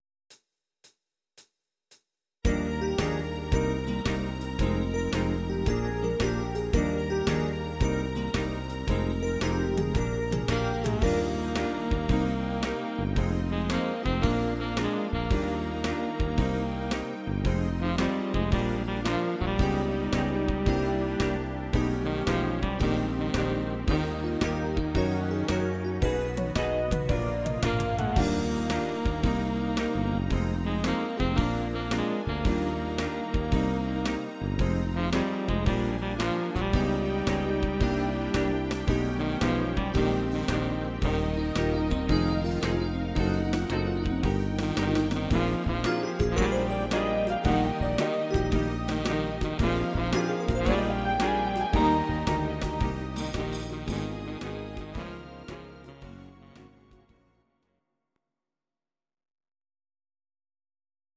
Bar Piano